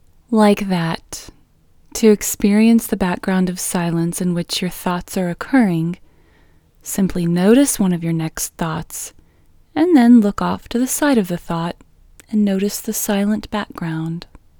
QUIETNESS Female English 8
Quietness-Female-8-1.mp3